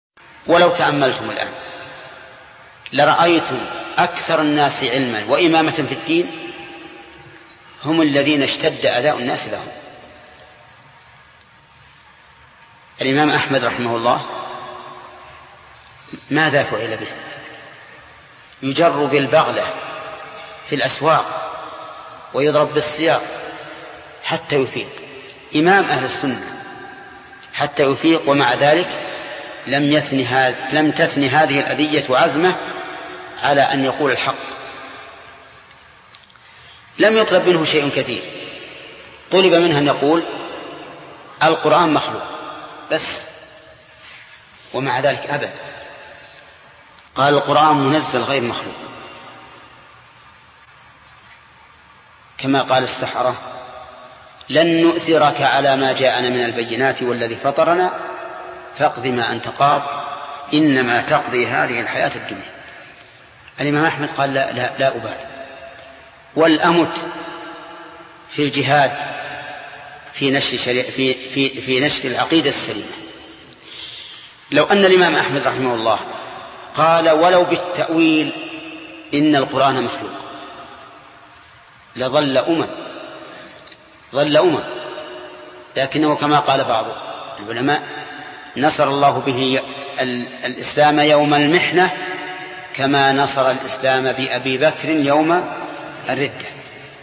القسم: من مواعظ أهل العلم
Download audio file Downloaded: 832 Played: 1942 Artist: الشيخ ابن عثيمين Title: من صبر الإمام أحمد رحمه الله Album: موقع النهج الواضح Length: 1:37 minutes (457.16 KB) Format: MP3 Mono 22kHz 32Kbps (VBR)